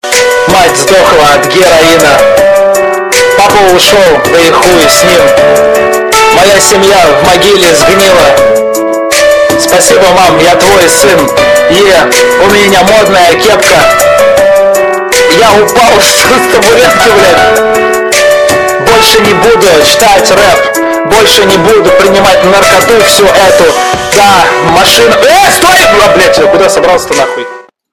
Category: TikTok Soundboard